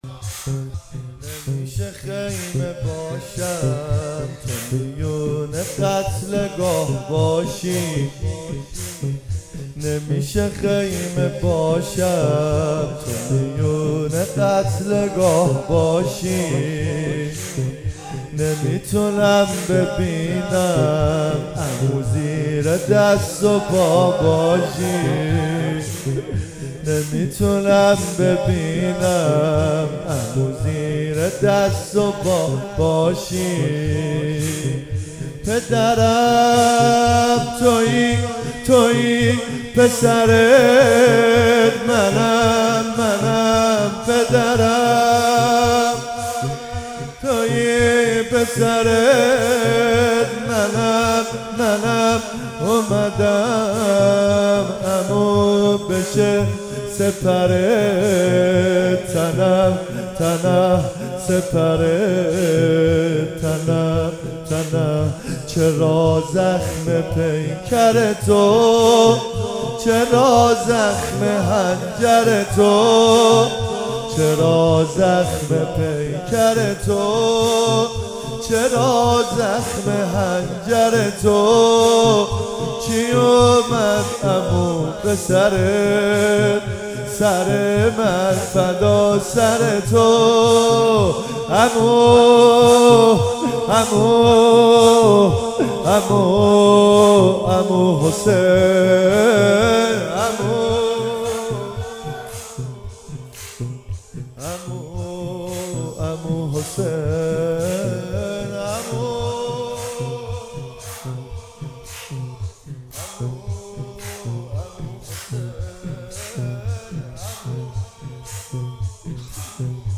شب پنجم محرم97 - زمینه